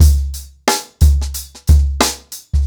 TheStakeHouse-90BPM.33.wav